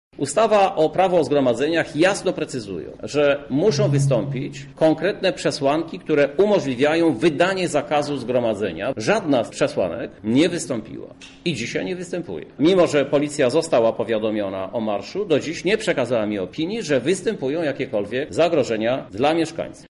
Sprawę komentuje Krzysztof Żuk, prezydent Lublina: